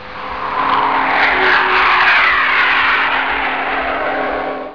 File: "Mach Patrol flying (fade in/out)" (Mach Patrol in volo (fade in/out))
Type: Sound Effect